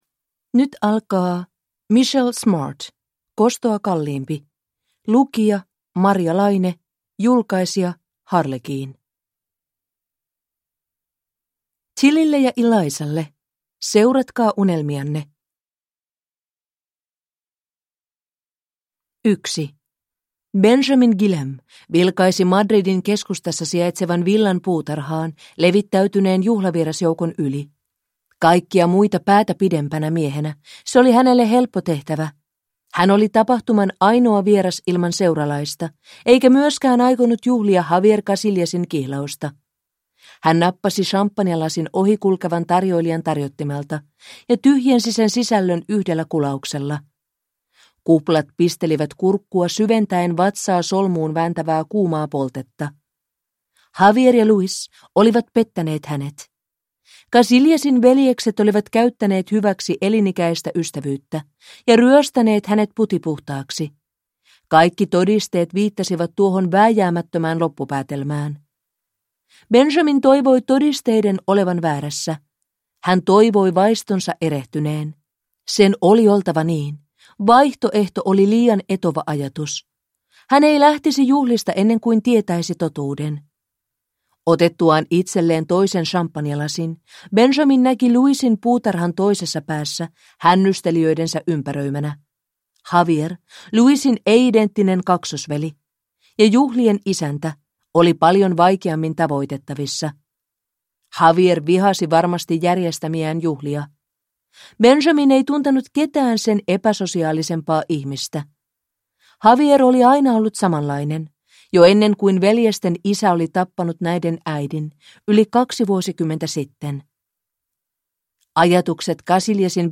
Kostoa kalliimpi – Ljudbok – Laddas ner